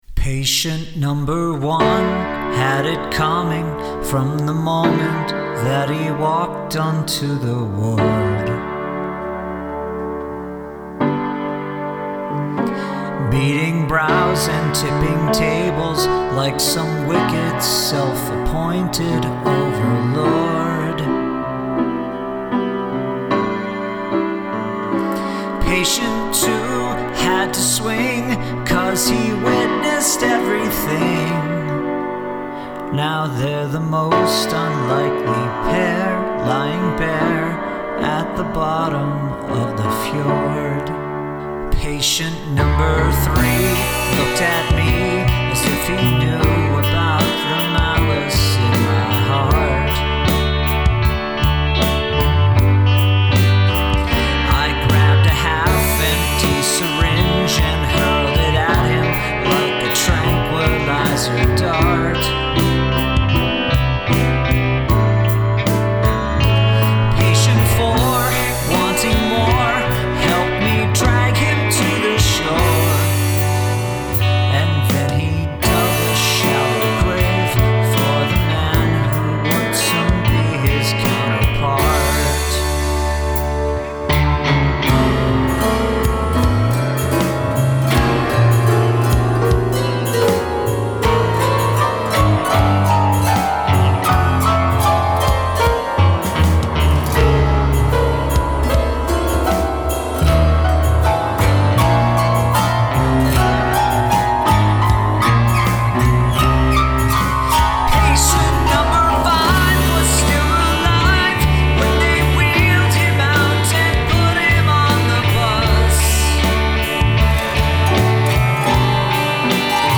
Significant use of cacophony.